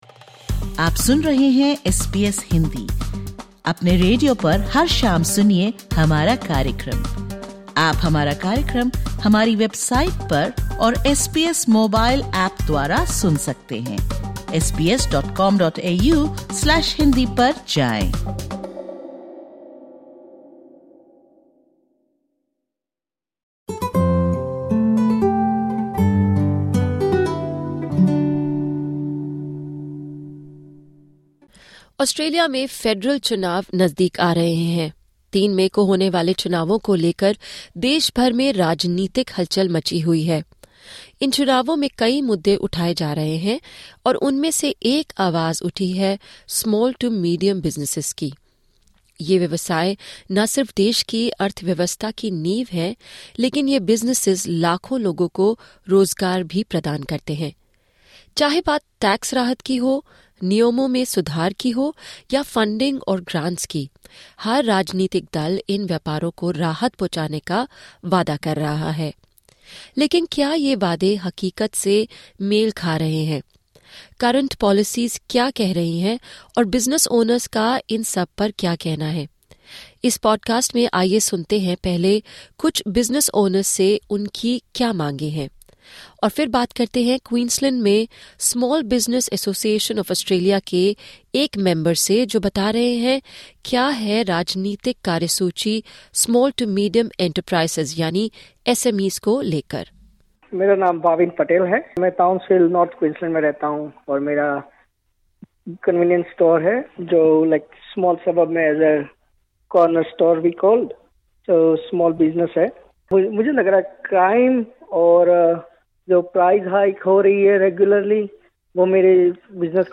With the federal election scheduled for 3 May, small and medium-sized businesses across Australia are raising concerns over inflation, crime rate, and how government funding is being allocated. In this segment, business owners share the challenges they face and their messages for the next government. We also hear from a representative of the Small Business Association of Australia (SBAA), who weighs in on how current policies are affecting SMEs and what changes they believe are needed.